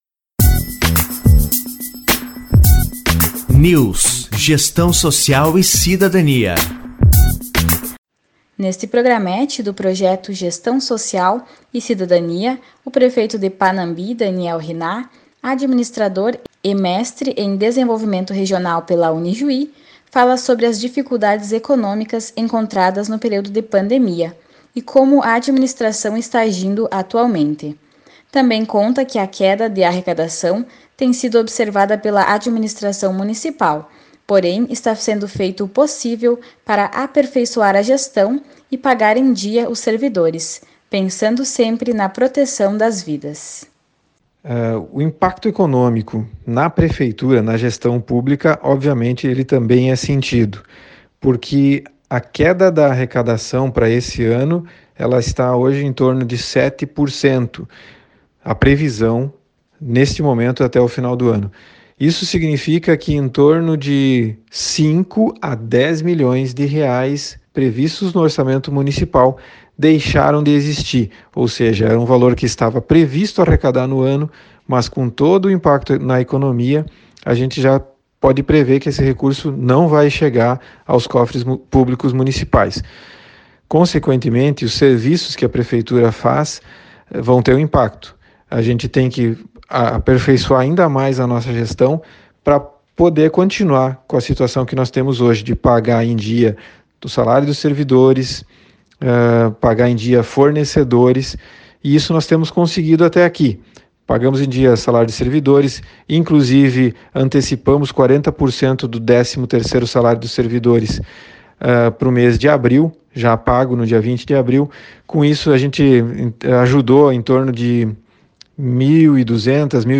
Entrevistado: Administrador, Mestre em Desenvolvimento Regional pela Unijuí e Prefeito de Panambi, Daniel Hinnah.